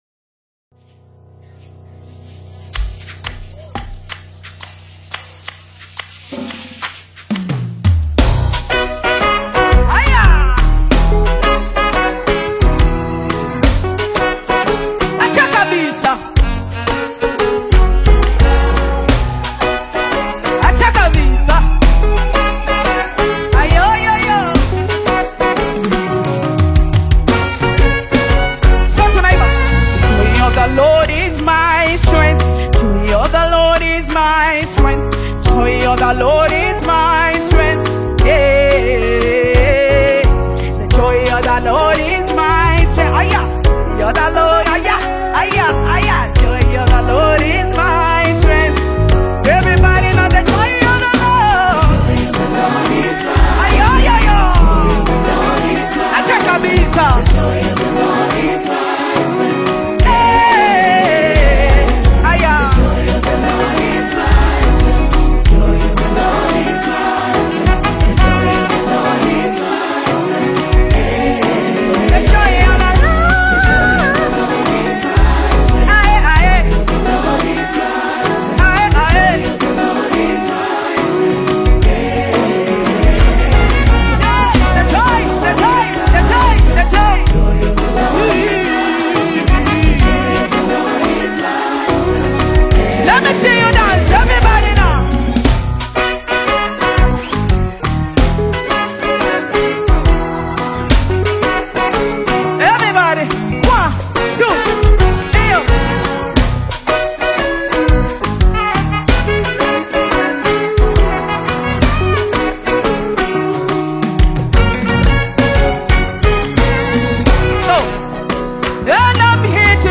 AudioGospel
Genre: Gospel